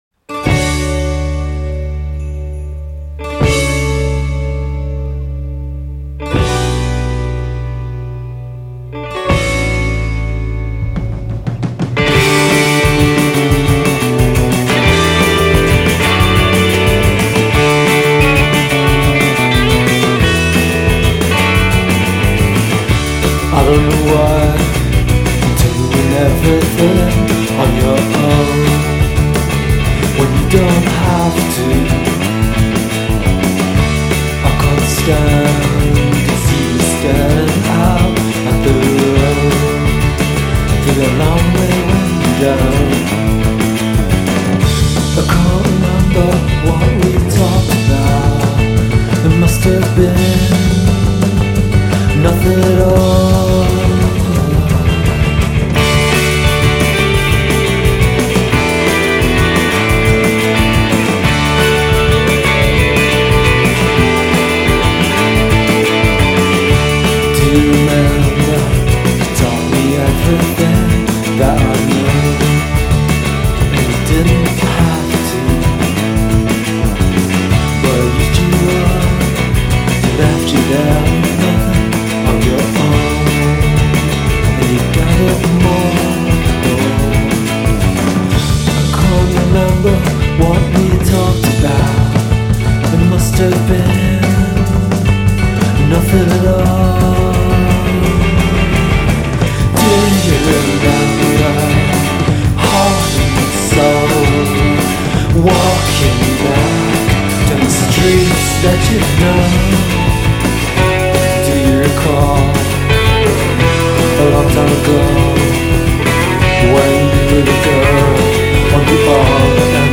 è un disco di indie rock fatto di chitarre obsolete
e il versante più nervoso e acerbo dell'indiepop
batteria
basso